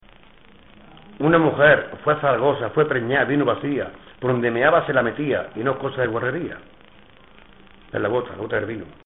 Materia / geográfico / evento: Adivinanzas Icono con lupa
Secciones - Biblioteca de Voces - Cultura oral